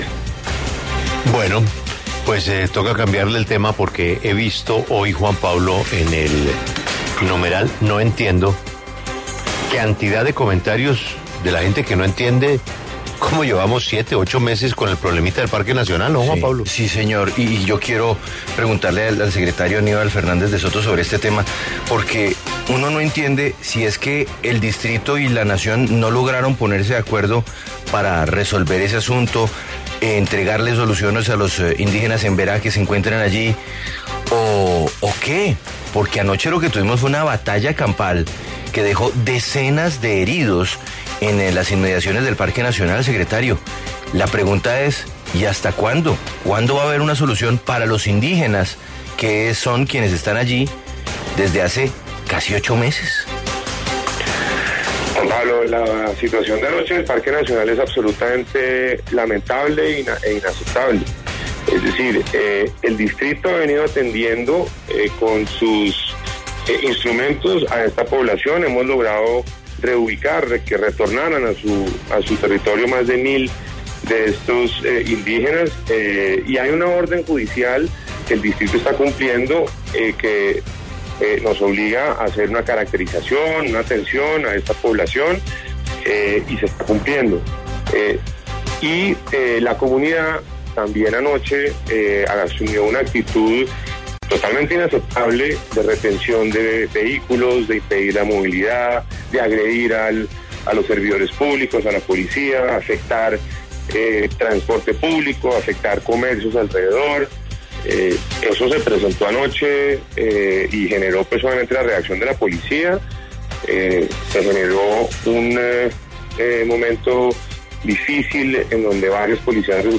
Escuche aquí la entrevista completa a Aníbal Fernández, secretario de Seguridad de Bogotá, en La W: